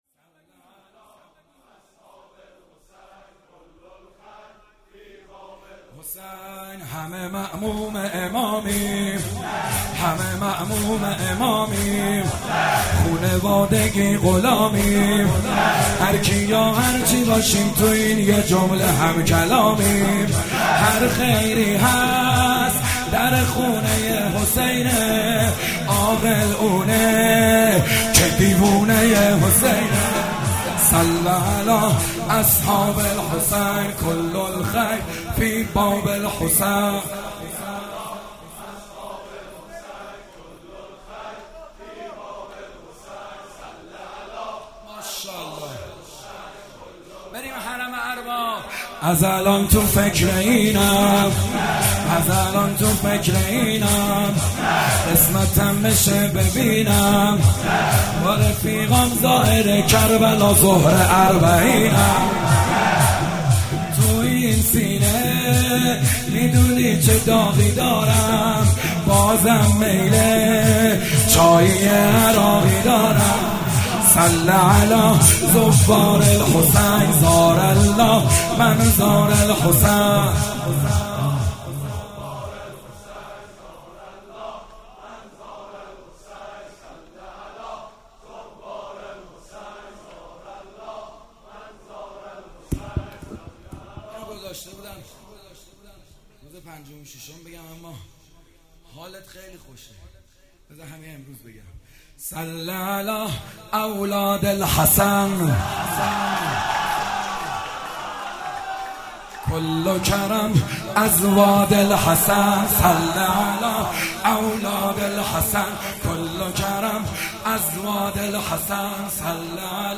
تک جدید